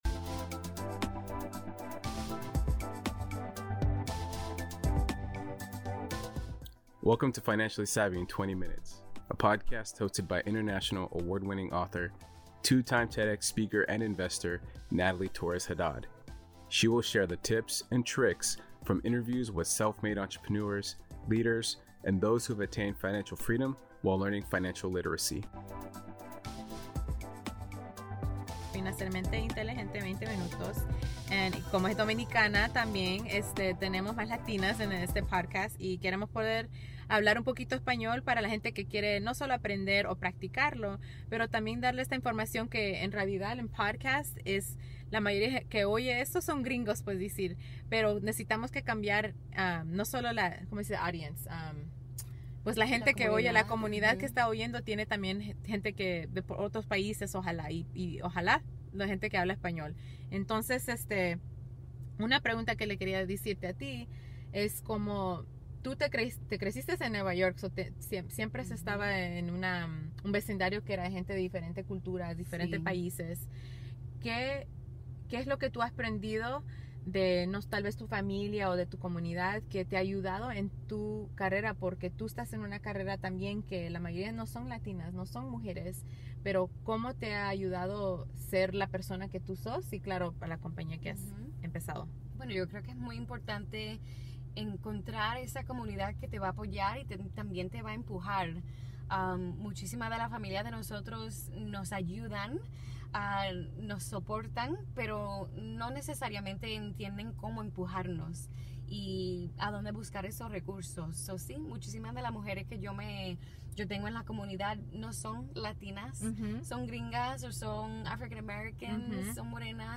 This month we will be sharing never before heard intimate interviews.